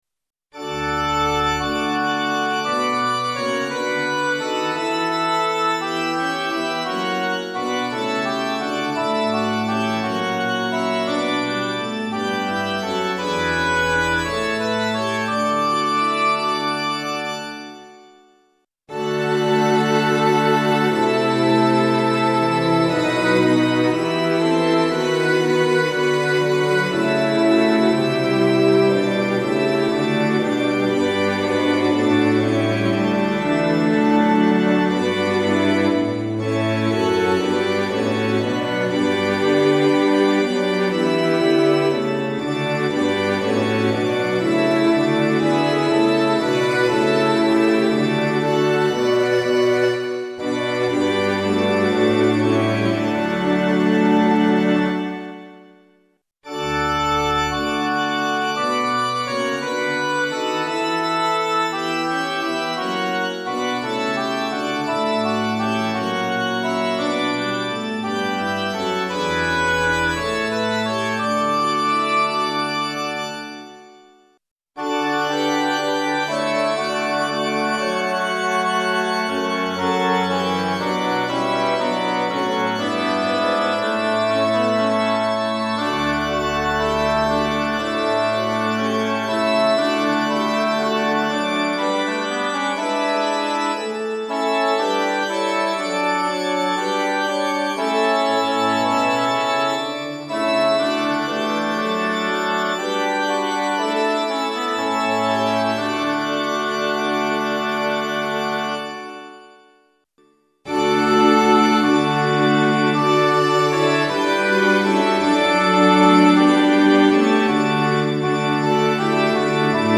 混声四部合唱+器楽 Four-part mixed chorus with Instruments
0.9.9.1 D Choir(S,A,T,B)